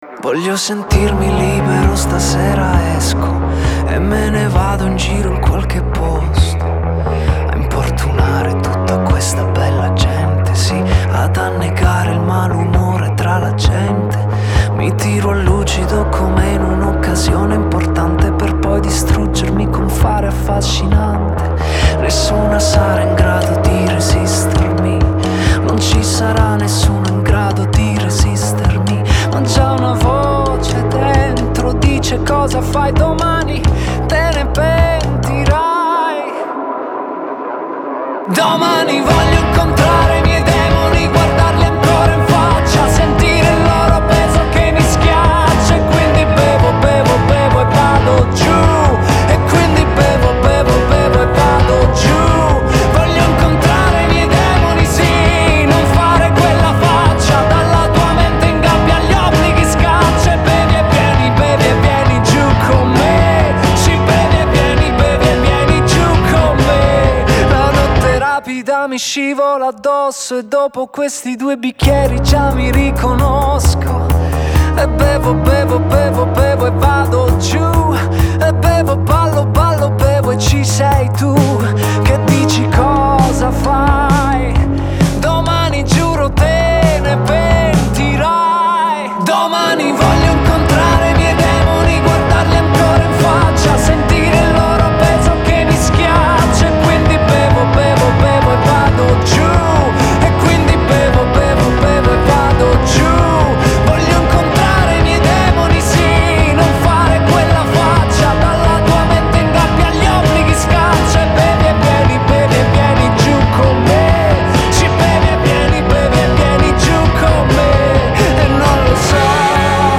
Genre: Pop Rock, Indie, Alternative